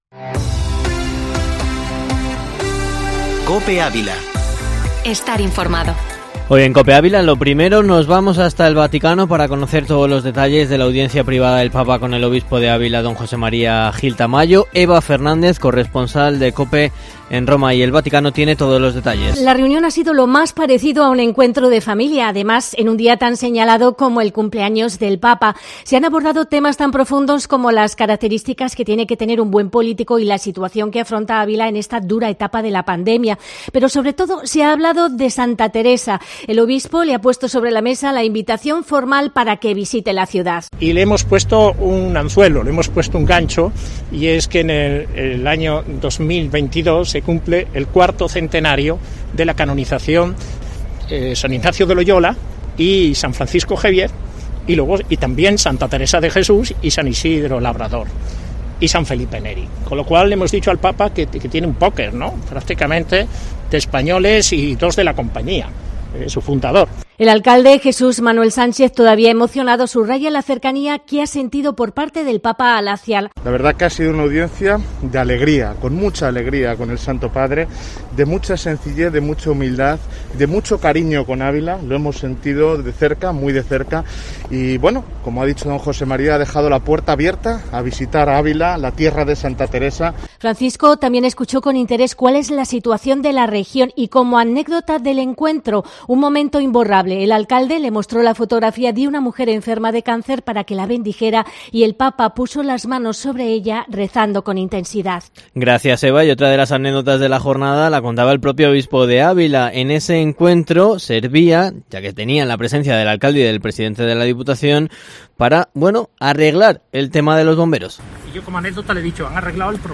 Informativo Matinal Herrera en COPE Ávila 18/12/2020